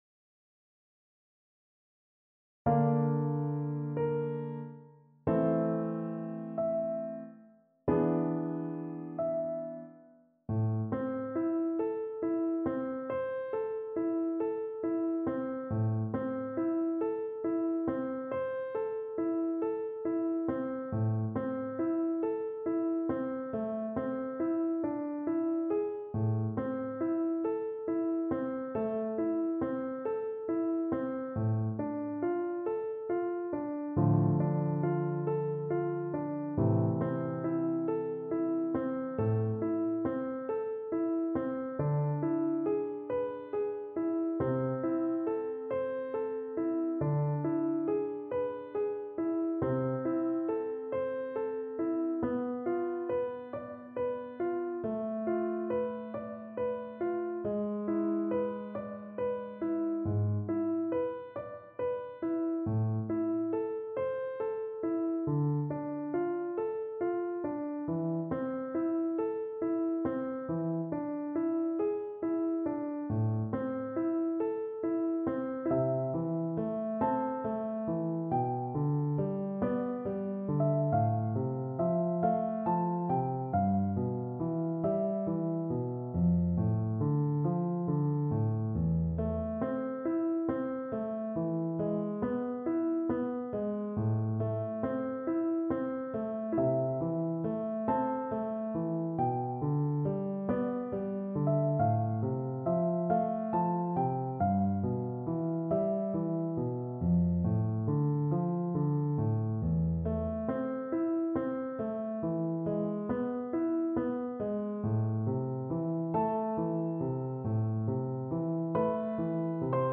4/4 (View more 4/4 Music)
Andante cantabile =46
Classical (View more Classical Soprano Voice Music)